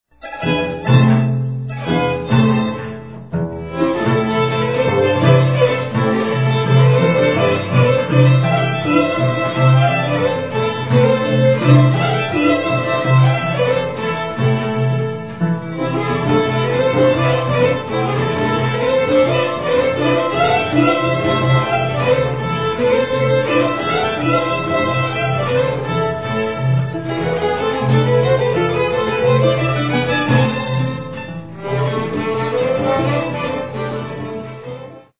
Classic Andean harp and violin music